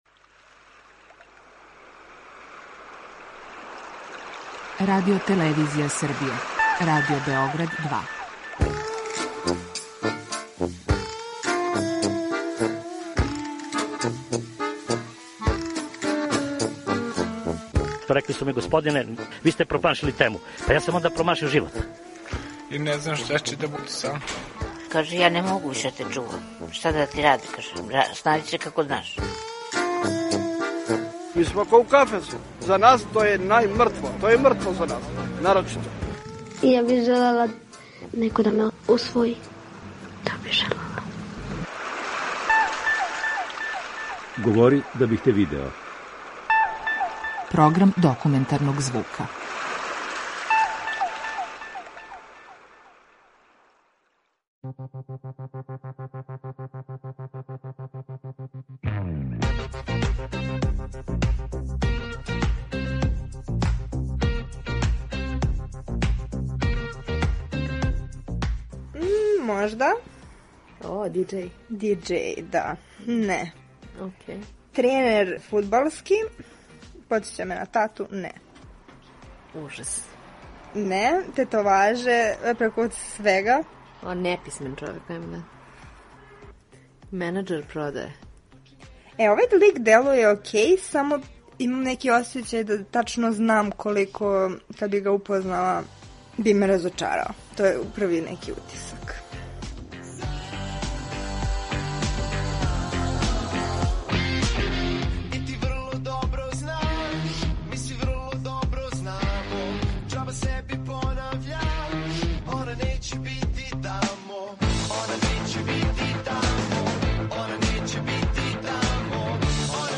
Документарни програм
Такозване „дејтинг" апликације све су заступљеније у свакодневном животу младих данас. Своја искуства са Тиндера, најпопуларније апликације за упознавање у Србији, поделиће са нама неколико њених корисника.